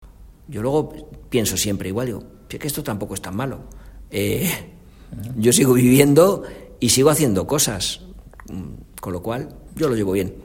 De hablar pausado y tranquilo